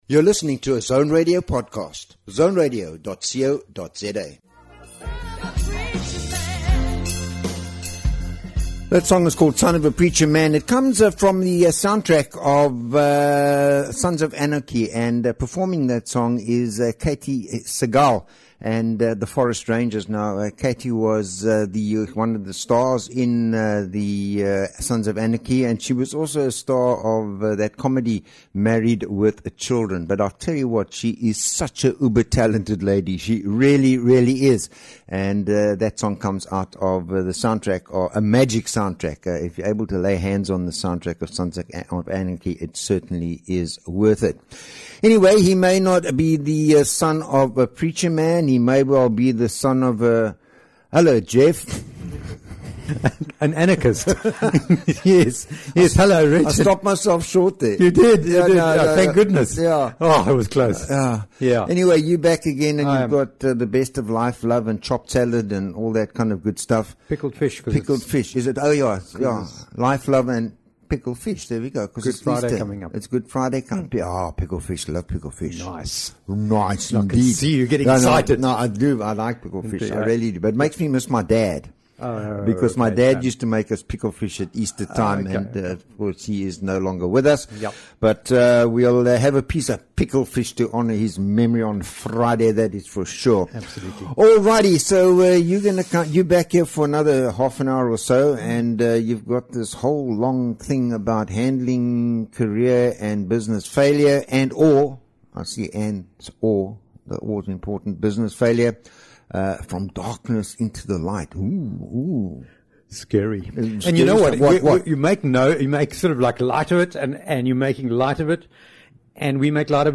is a bi-weekly radio show exploring the rich tapestry of human experiences.